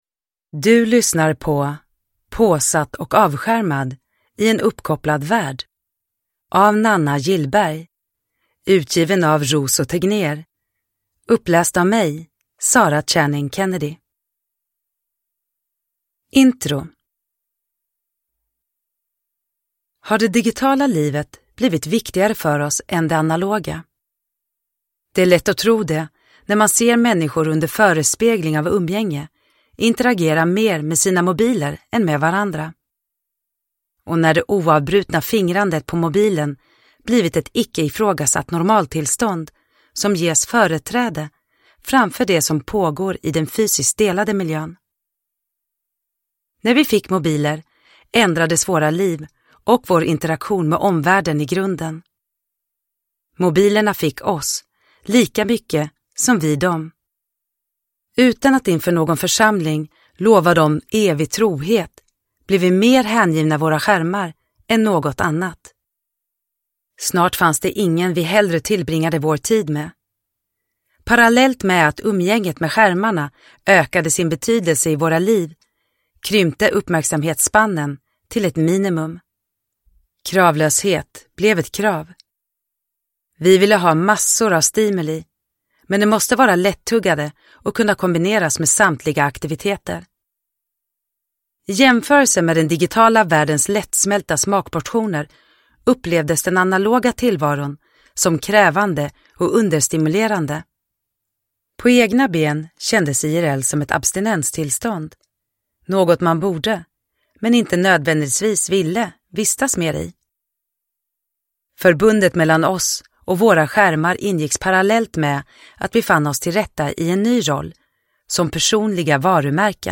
Påsatt och avskärmad – i en uppkopplad värld – Ljudbok – Laddas ner
Produkttyp: Digitala böcker